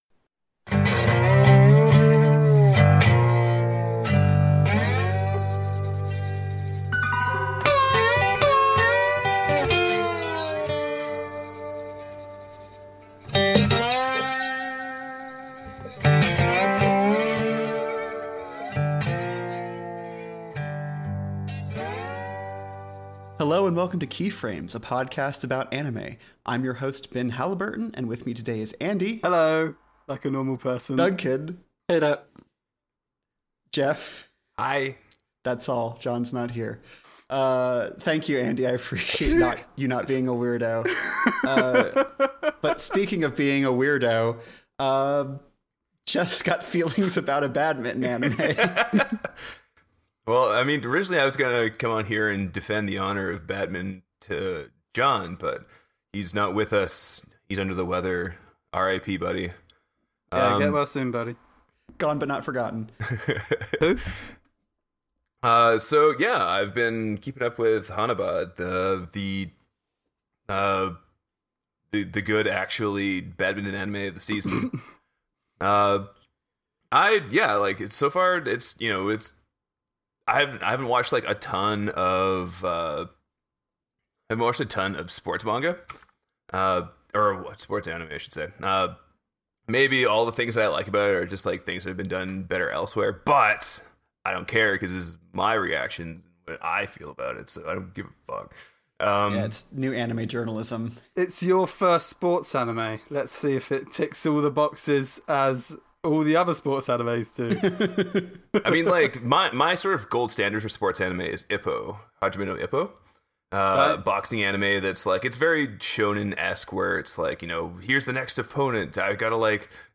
Key Frames is a podcast about anime. Join a panel of fans from different walks of life as they discuss what they're watching and spotlight genre classics!